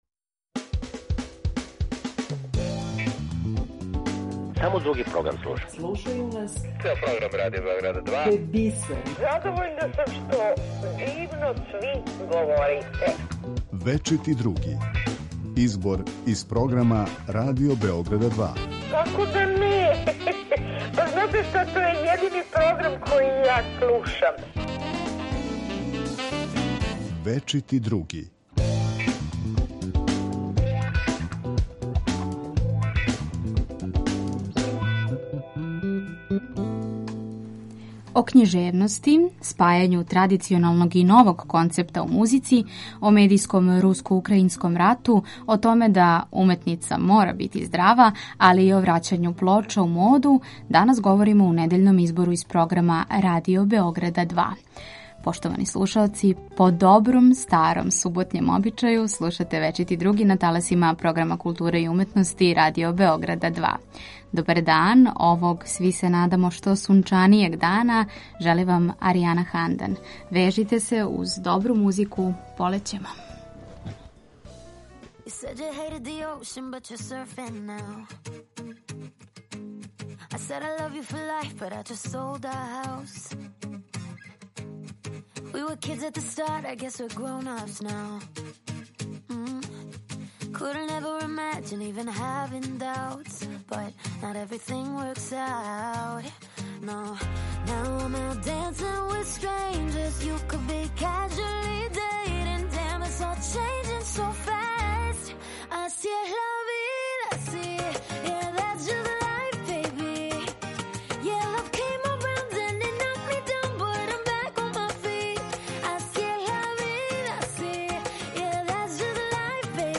У недељном избору из програма Радио Београда 2 за вас издвајамо делове из емисија Клуб 2, Речено и прећутано, Радар, Од злата јабука, Дионис...